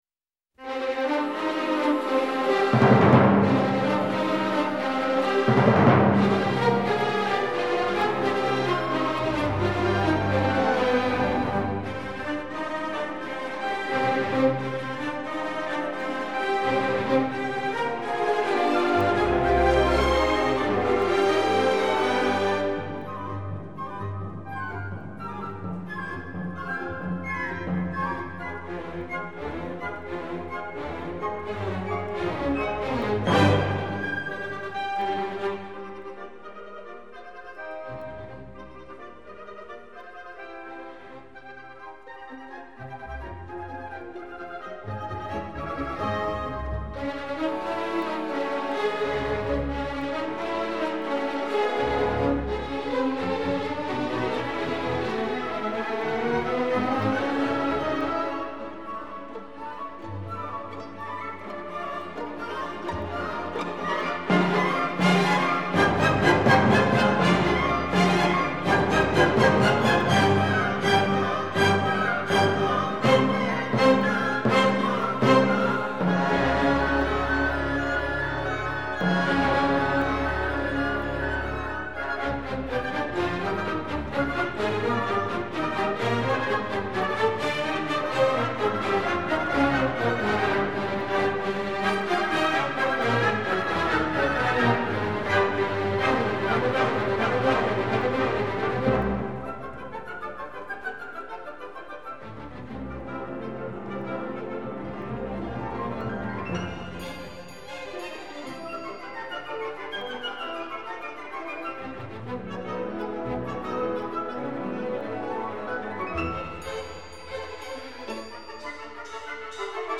管风琴
演奏 Orchestre National de I’O.R.T.F 法国国家电台管弦乐团 指挥 Jean Martinon
快板的地方